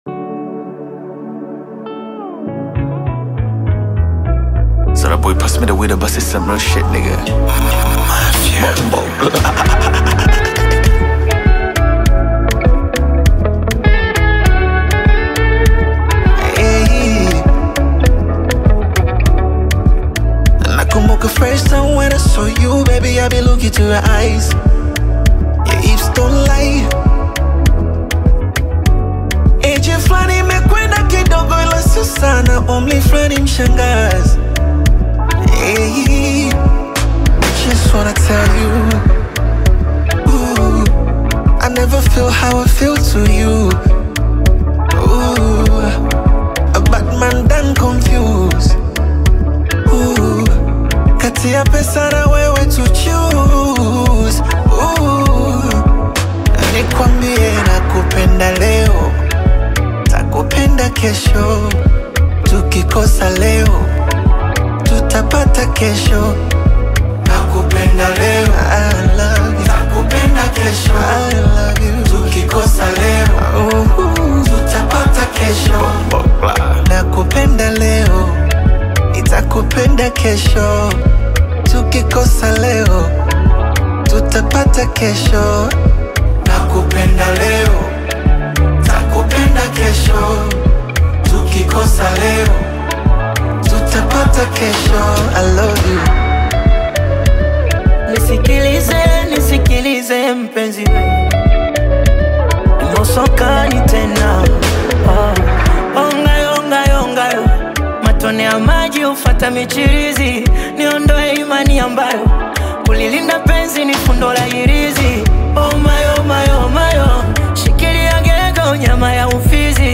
smooth Afro-Pop/Bongo Flava single
rich soulful vocals, creating an emotional duet